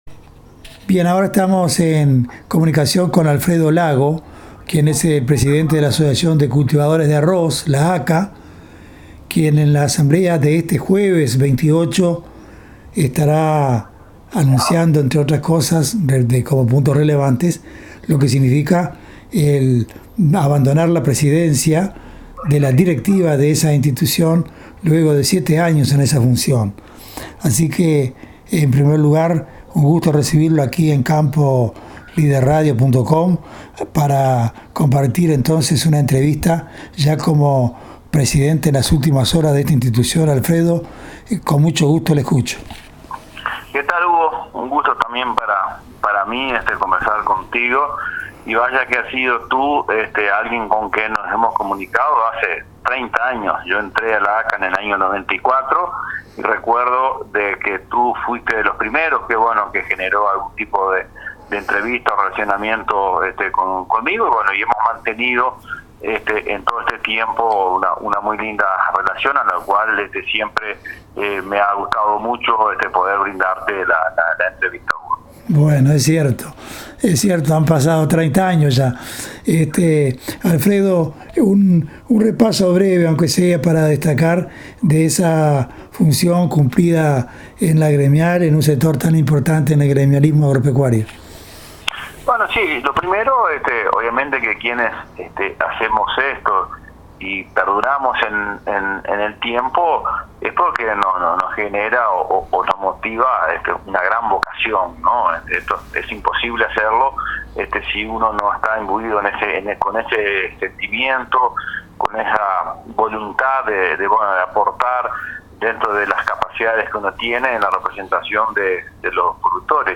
A continuación, la entrevista exclusiva que mantuvimos: